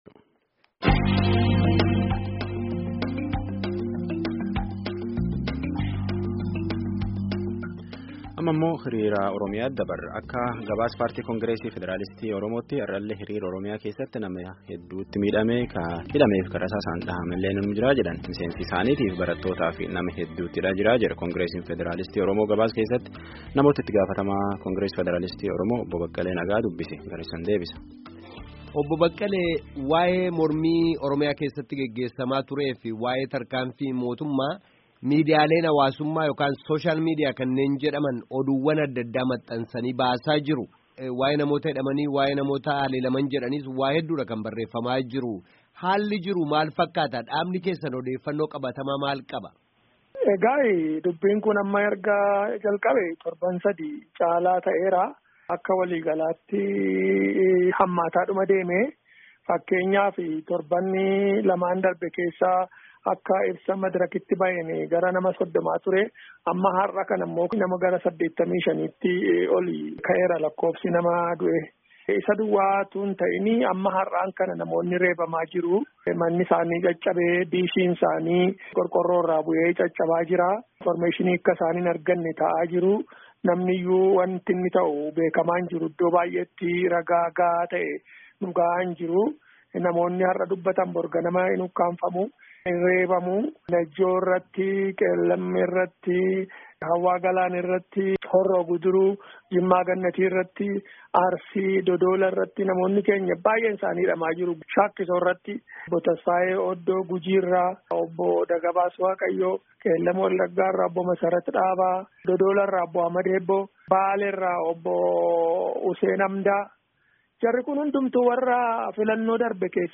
Gabaasaa guuutuu dhaggeeffadhaa